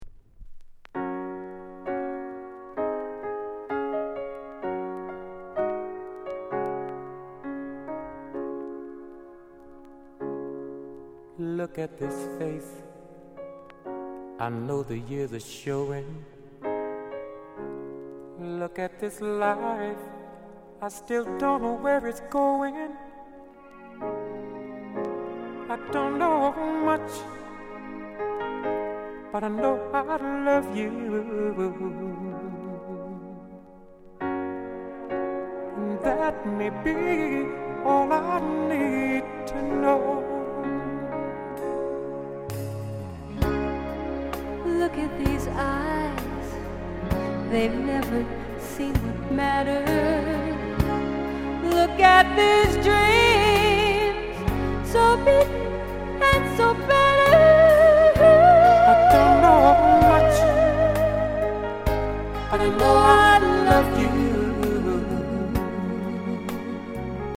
SOUND CONDITION EX-
NICE BALLAD